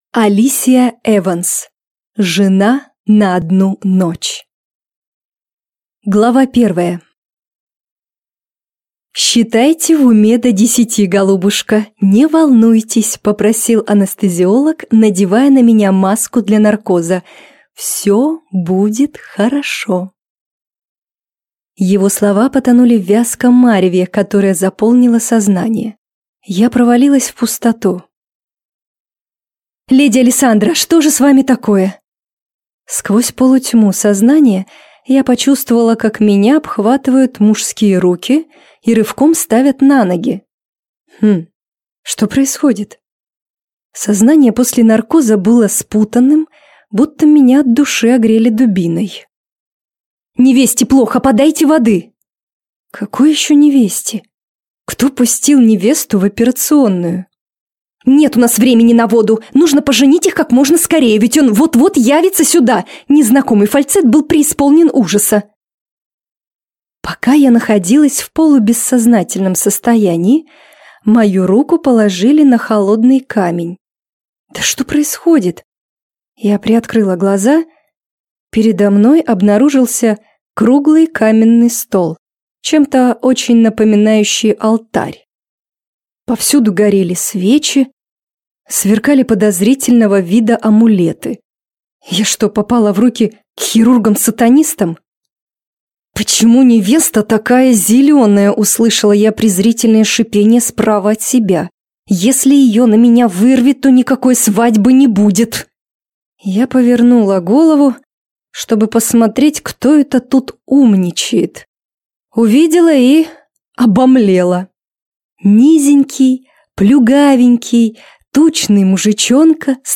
Аудиокнига Жена на одну ночь | Библиотека аудиокниг